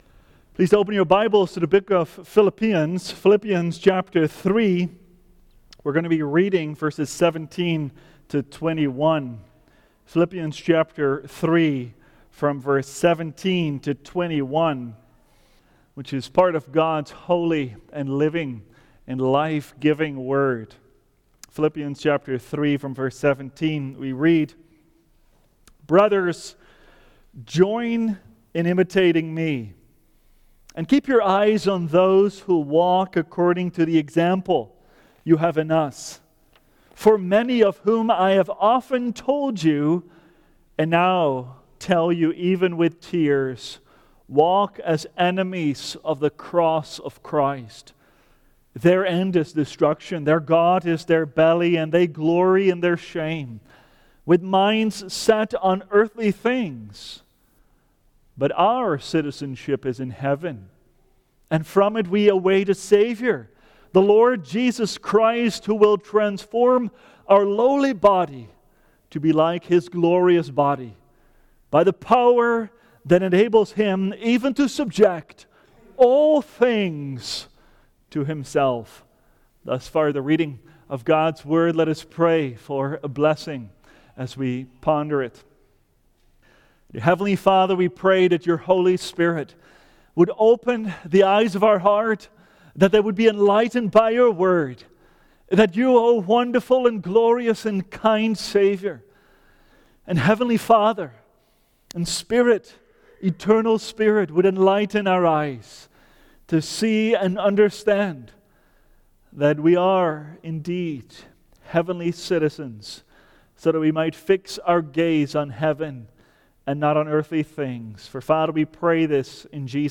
Ascension Day Sermons